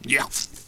spearman_attack1.wav